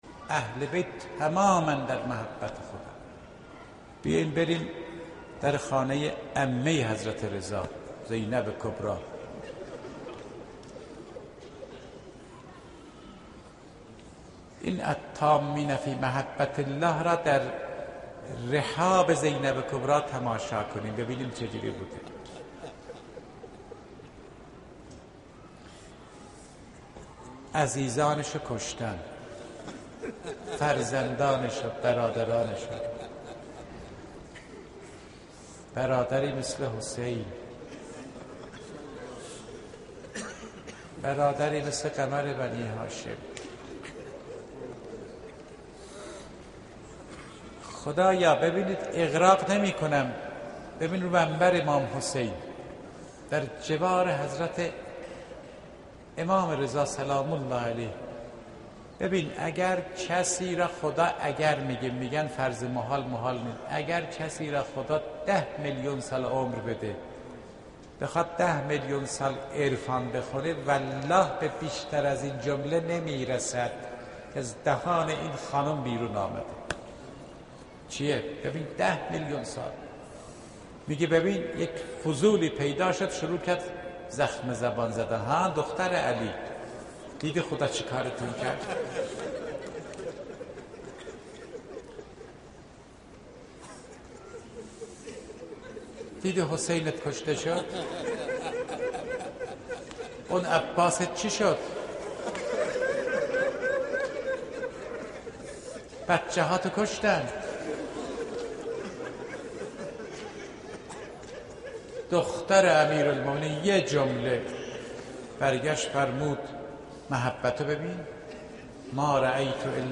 روضه حضرت زینب